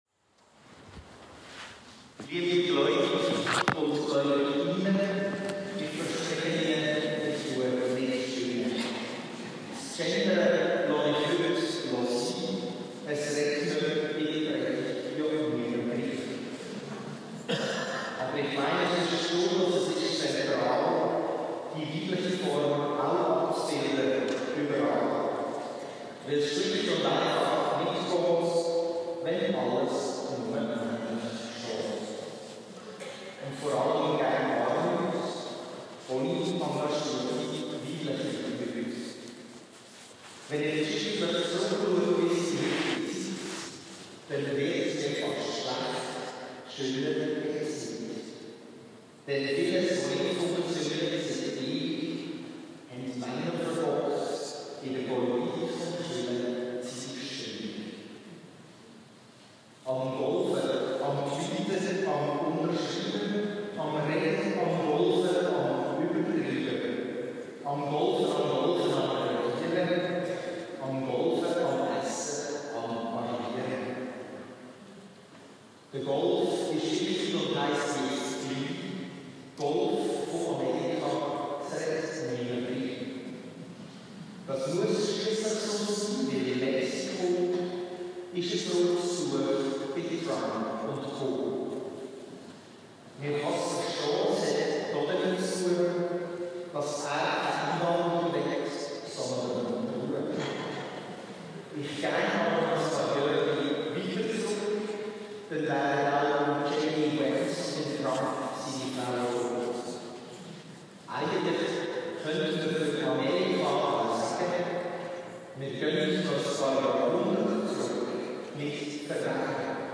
Die Guuggermesse fand in der Kirche St. Michael statt und erfreute viele Menschen. Das Thema der Barfuessfäger (Olympiade der Tiere) wurde im Gottesdienst aufgenommen und regte zum Nachdenken an, wie wir mit Stärke, Geduld und Macht umgehen.
Zum_Nachhören_-_Fasnachtspredigt.mp3